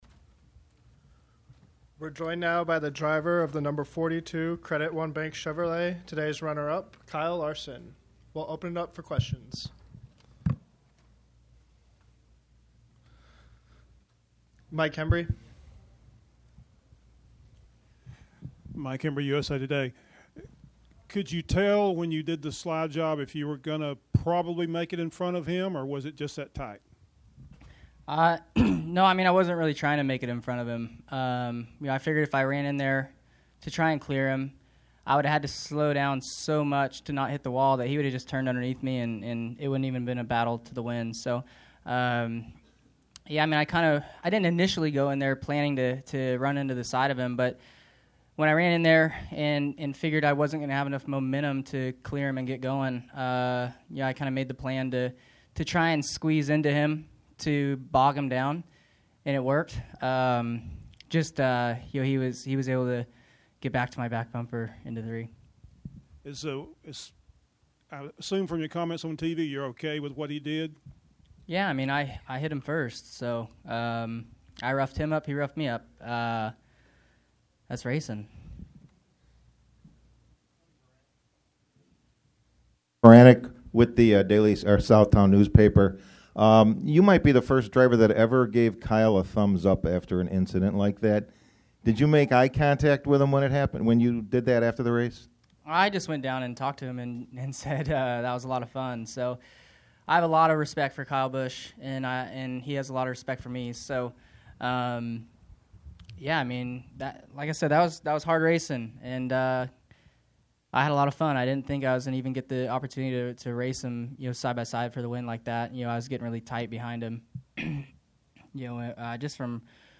Media Center Interviews: